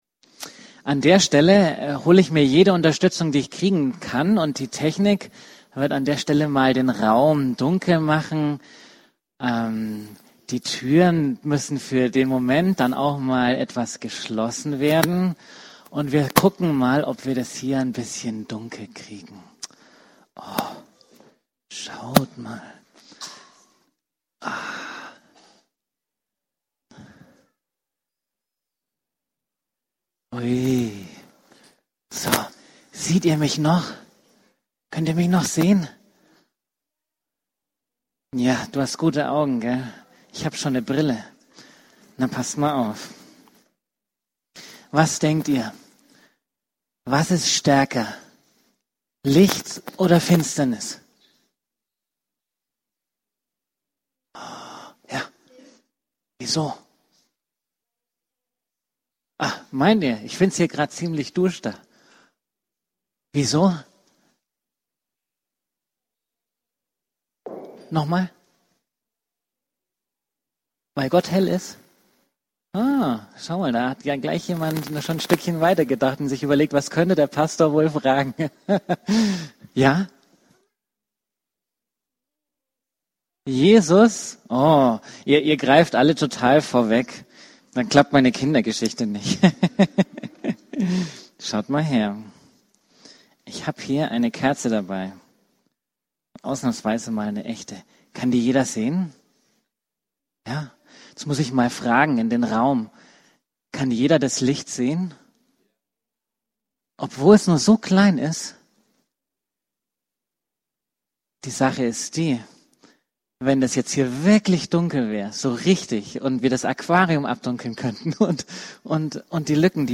Kindersegnung am 23.11.20
Predigten